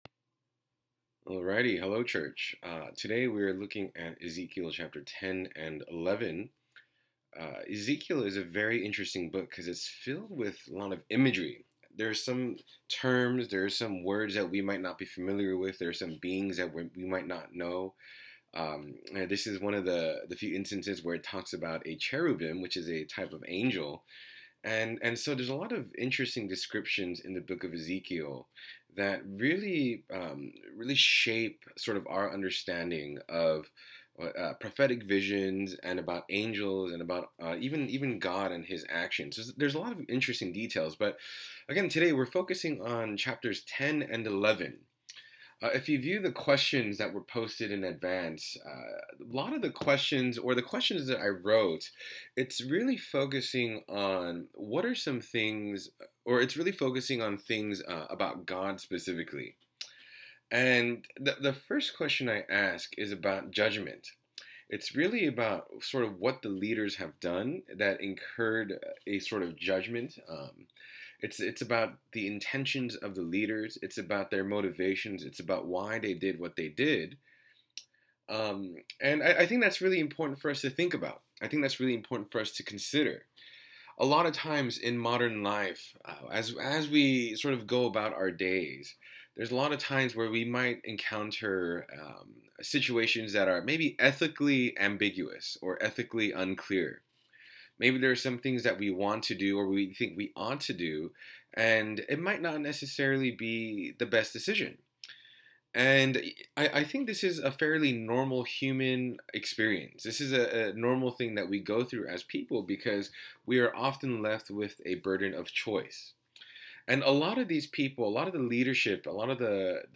Scripture & Application